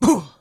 Male Hit 4.wav